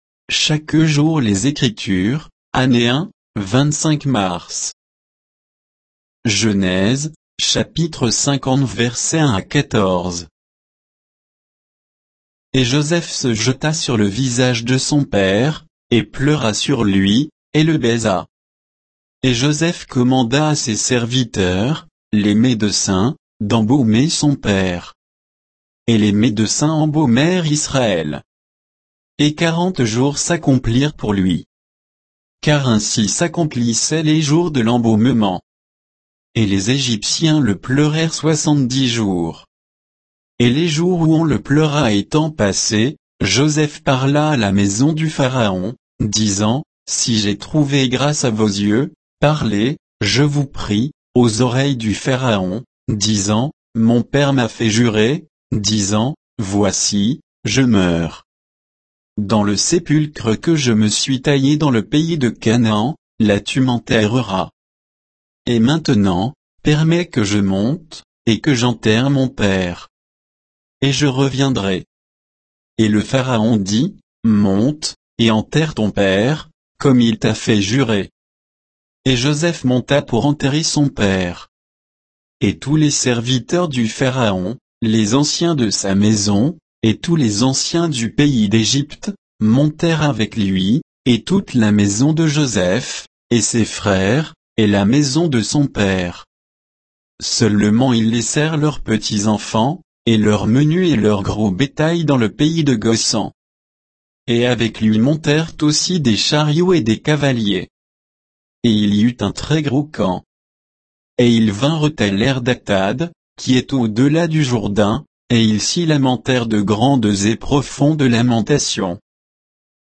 Méditation quoditienne de Chaque jour les Écritures sur Genèse 50